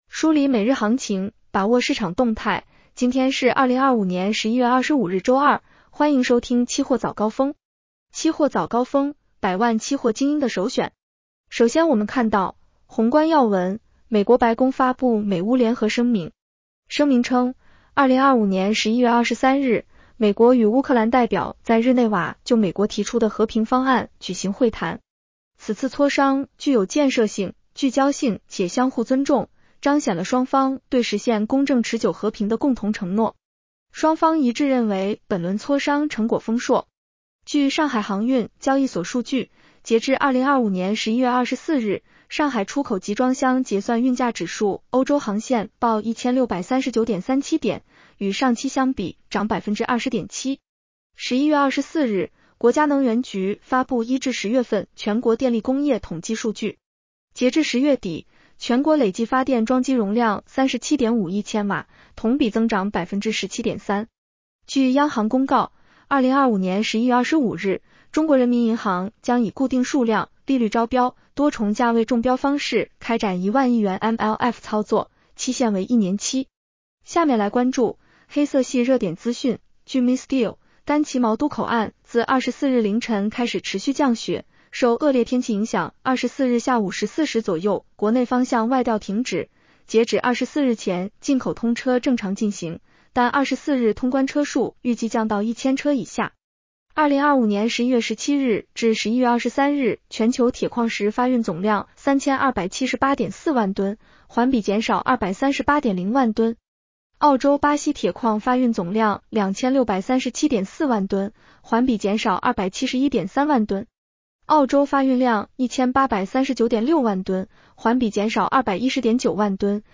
期货早高峰-音频版 女声普通话版 下载mp3 热点导读 1.临汾古县2座煤矿于11月20-21日先后停产，2座煤矿核定产能共240万吨。